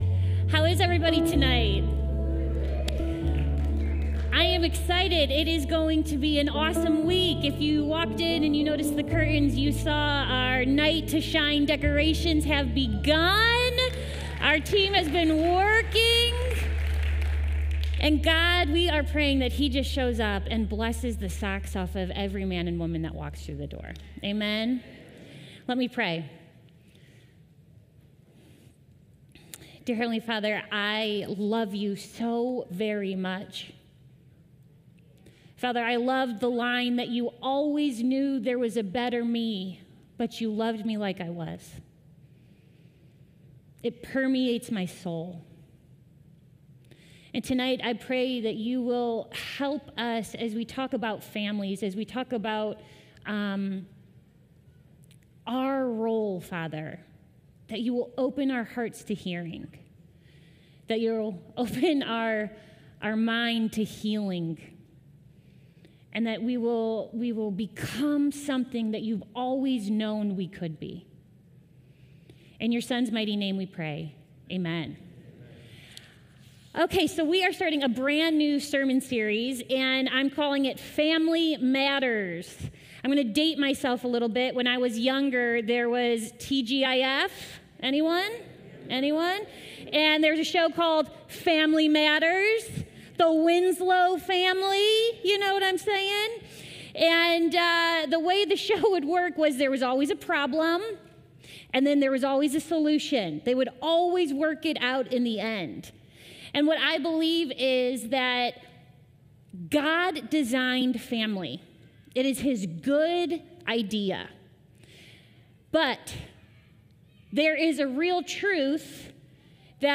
Christ Church Ohio – Columbia Station Campus Family Matters - Week 1 Feb 06 2025 | 00:34:29 1x 00:00 / 00:34:29 Subscribe Share Spotify RSS Feed Share Link Embed